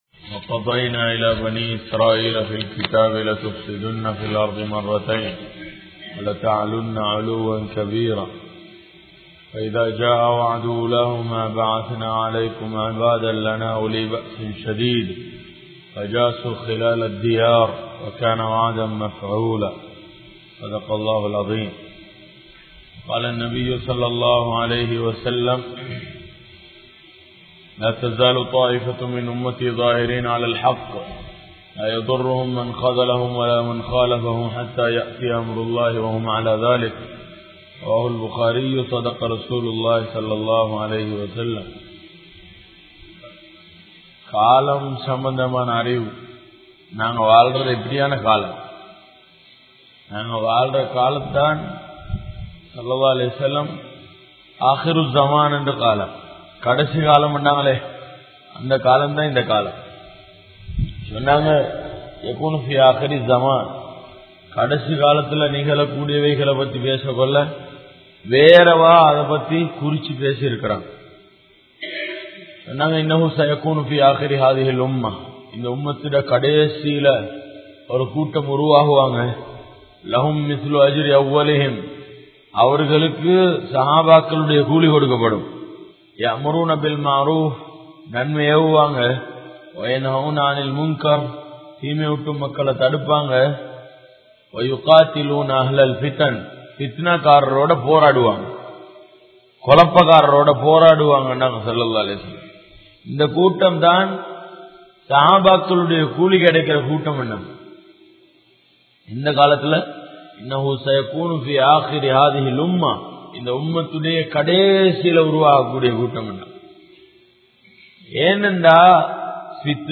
Unmaiyil Vilanga Vendiya Ilm Ethu?(உண்மையில் விளங்க வேண்டிய இல்ம் எது?) | Audio Bayans | All Ceylon Muslim Youth Community | Addalaichenai